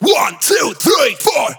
VR_vox_hit_1234_2.wav